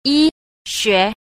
9. 醫學 – yīxué – y học